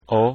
Давайте прослушаем произношение этих звуков:
ó (открытое “o”):